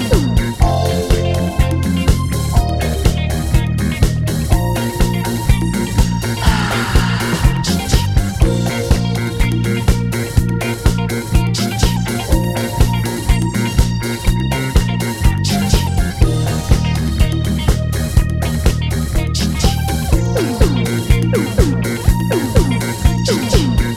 One Semitone Down Pop (1980s) 4:18 Buy £1.50